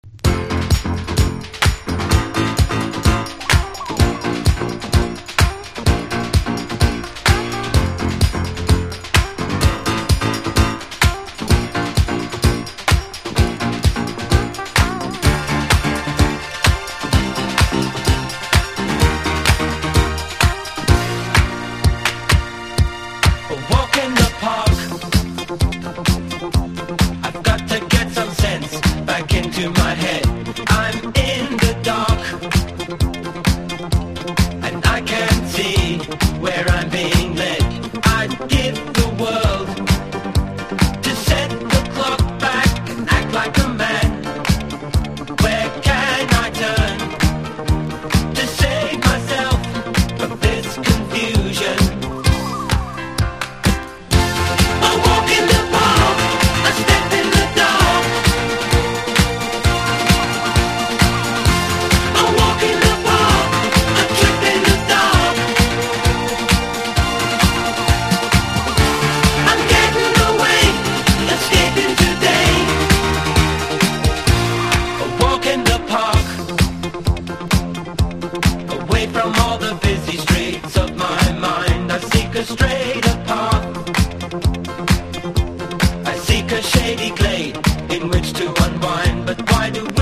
シンセサウンドをフューチャーしたディスコ・ナンバーを収録！